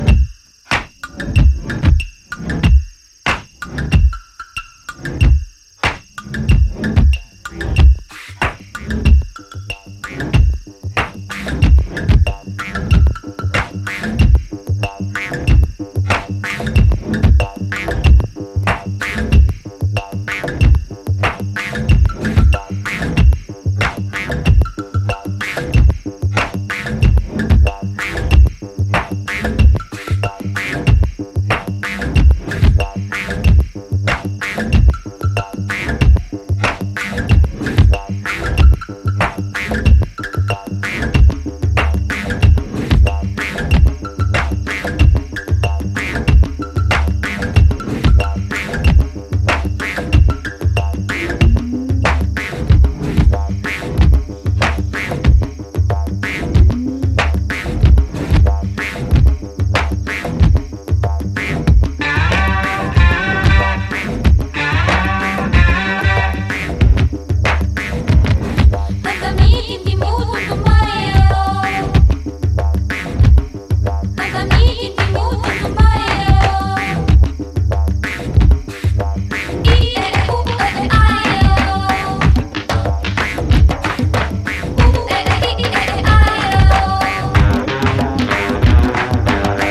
個性的なスロー・トライバル〜ワールド・ミュージックっぽいオリジナルをスタイリッシュにクラブ・トラック化！